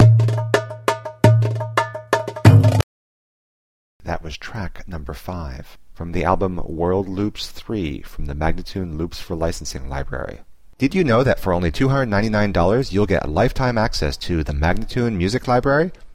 Instrumental samples in many genres.